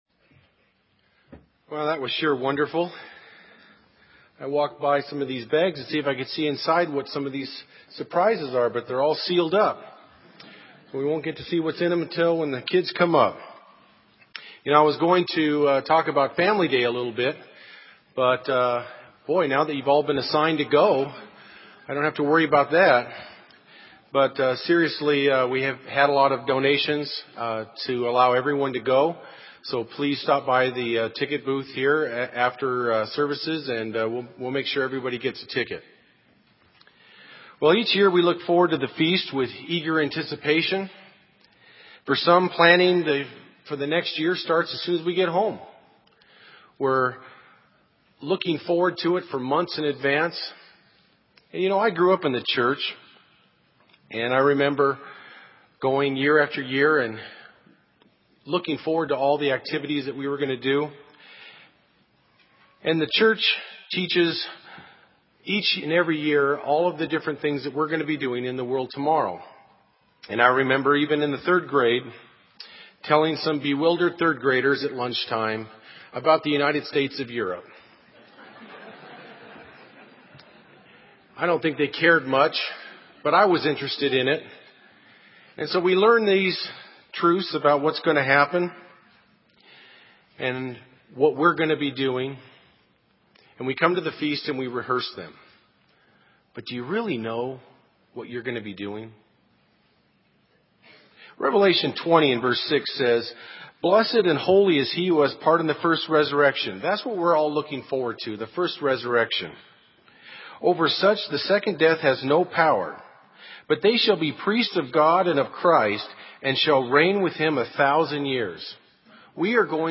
This sermon was given at the Bend, Oregon 2011 Feast site.